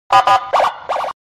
Nada notifikasi Sirine Polisi (Versi 2)
Suara sirine Polisi (Patroli) Nada notifikasi Sirine Polisi
Kategori: Nada dering
nada-notifikasi-sirine-polisi-versi-2-id-www_tiengdong_com.mp3